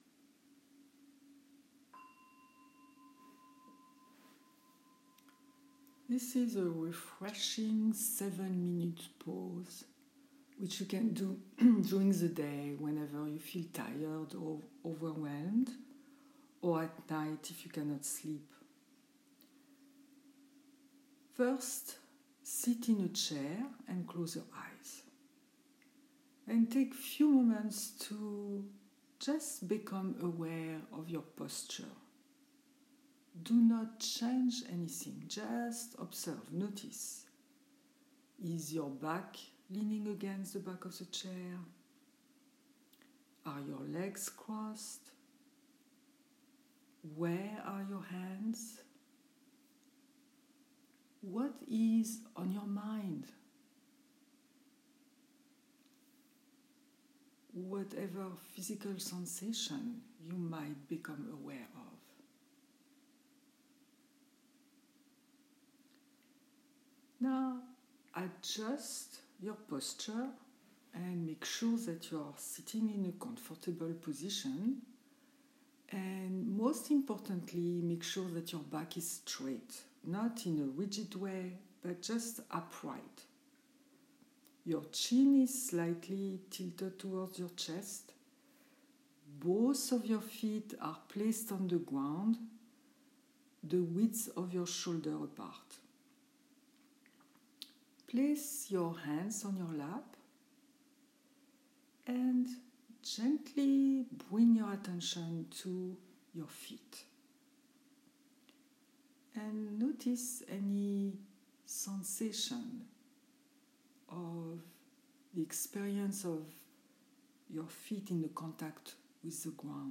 Meditation (ZOOM)